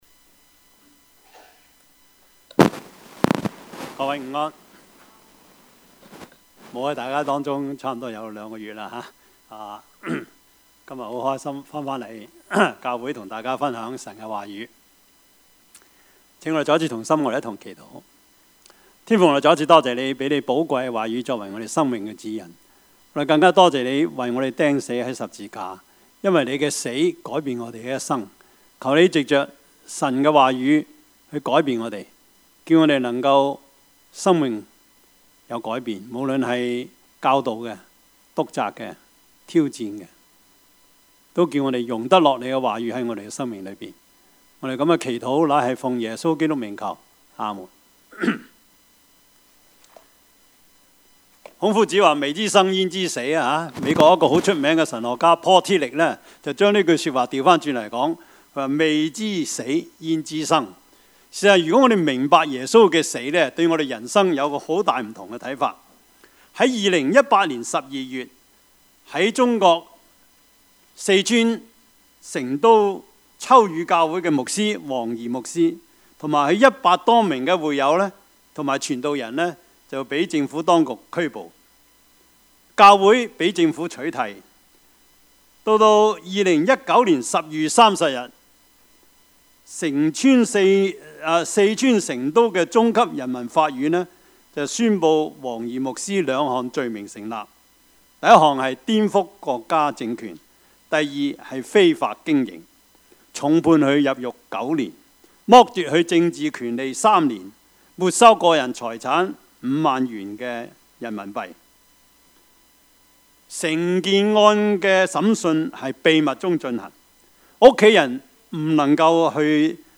Service Type: 主日崇拜
Topics: 主日證道 « 無能者的大能 大復興 »